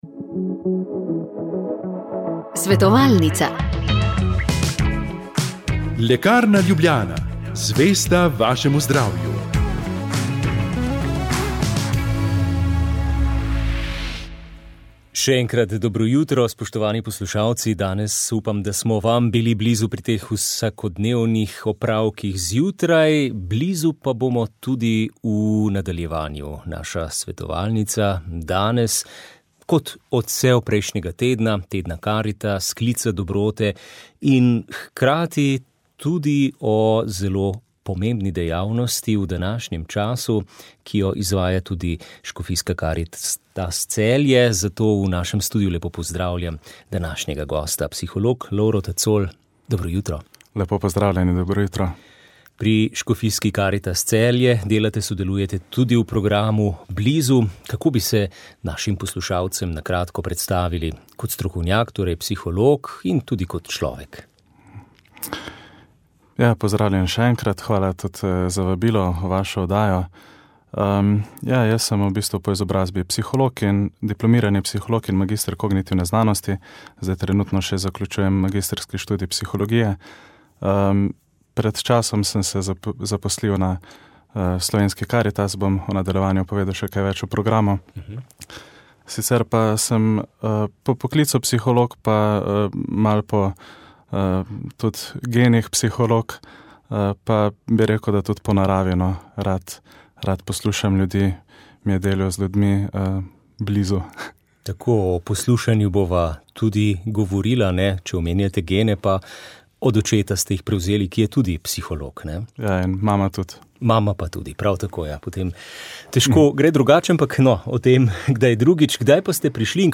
Oddaja je nastala na mini počitnicah Radia Ognjišče na Bledu.